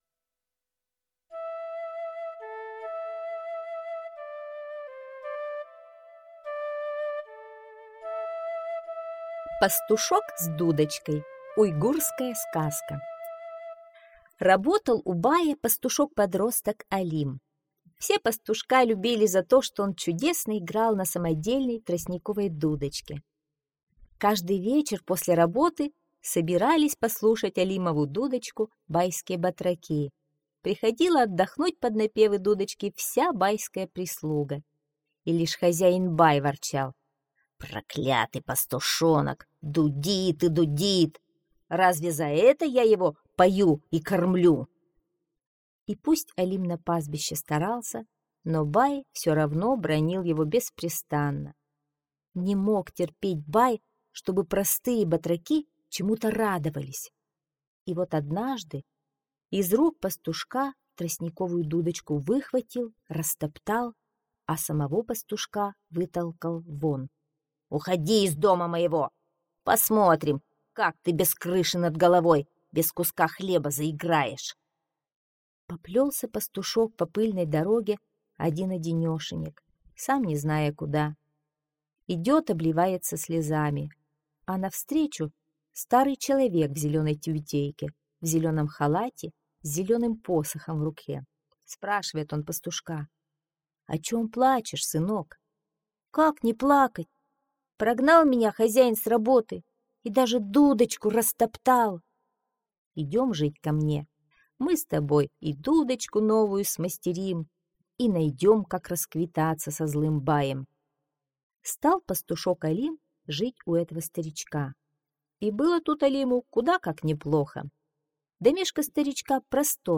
Пастушок с дудочкой - уйгурская аудиосказка - слушать онлайн